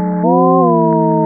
a) Convertemos cada uma das tensões elétricas medidas nos 6 sensores (no nariz eletrônico) em 6 sinais harmônicos na faixa entre 100 Hz e 1000 Hz, logo, numa faixa de sinais de áudio, através da modulação em frequência e amplitude de sinais senoidais. Ou seja, cada medida de tensão foi associada a uma nota musical.
• Gás de isqueiro: